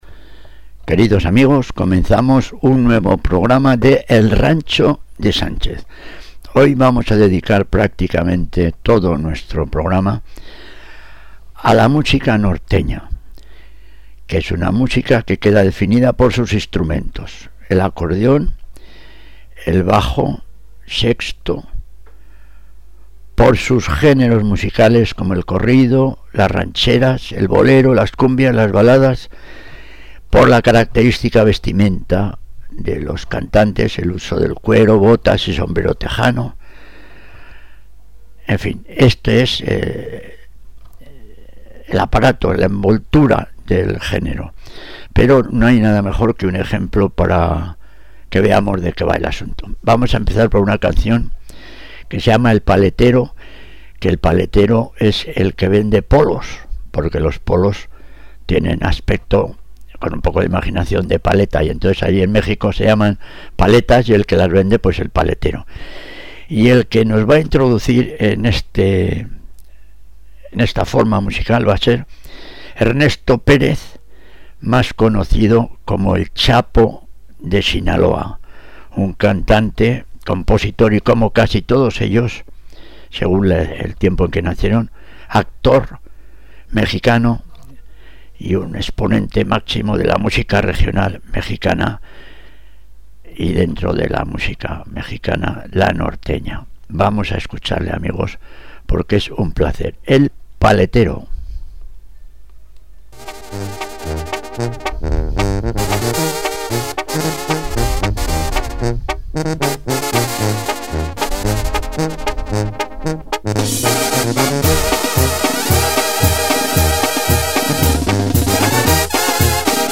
Un programa radiofónico sin igual dedicado a los amantes de la Canción Ranchera.